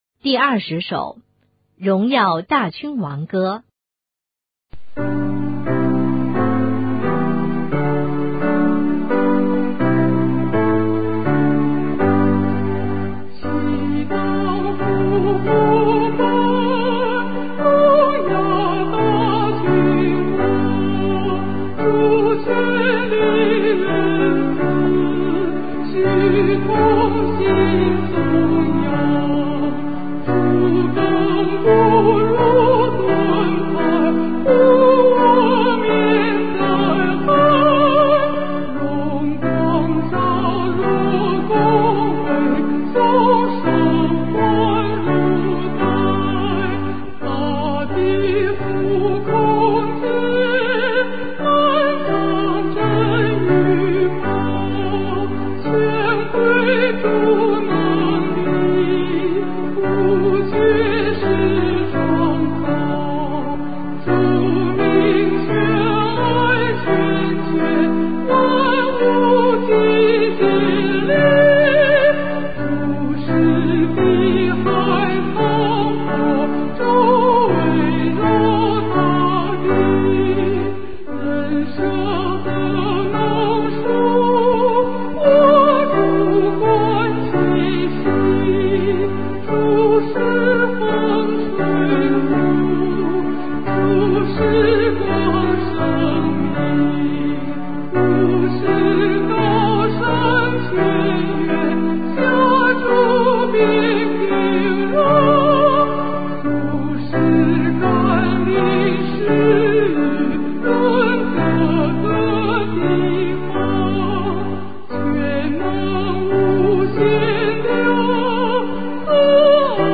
颂赞：《荣耀大君王歌》